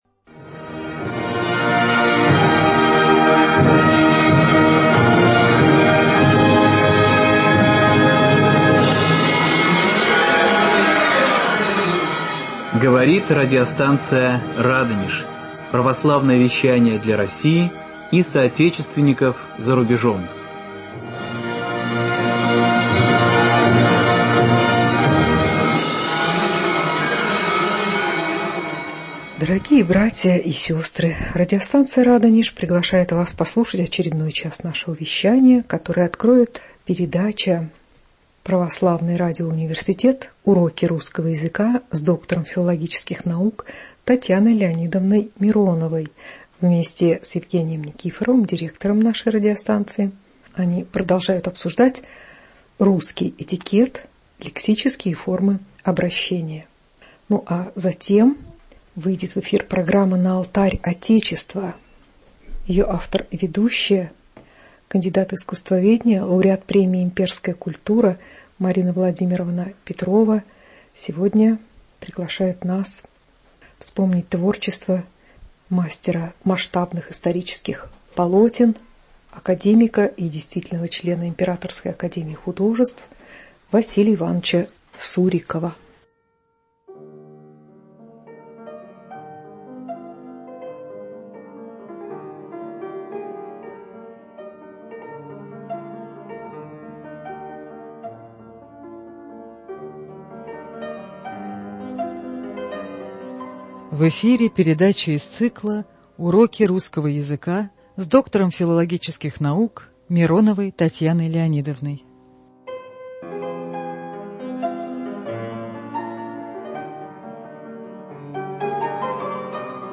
Православный радиоуниверситет Уроки русского языка. Этикет, обращения ч.2. Доктор филологических наук